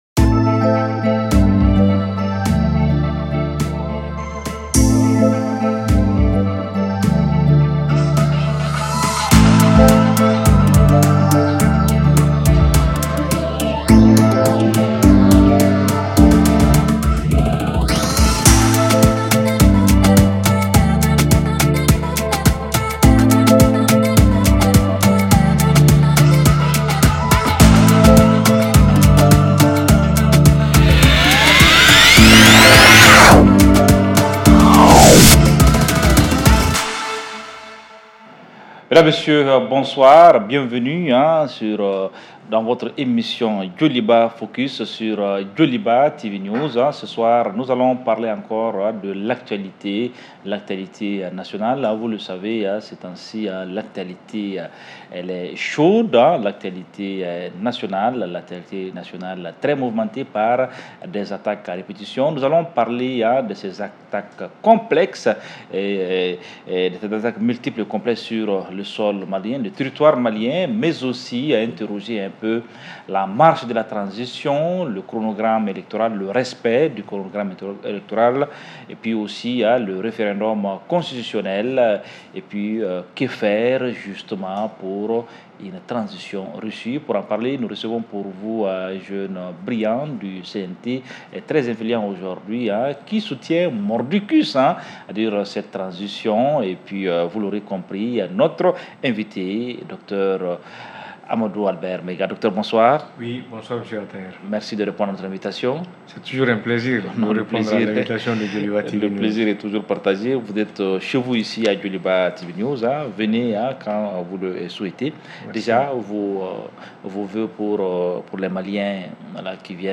En direct sur Joliba TV News en synchronisation avec la radio Joliba 105.0 FM à Bamako, 100.1 FM à Ségou et 103.8 FM à Sikasso ainsi que sur les pages JolibaTV / Joliba FM.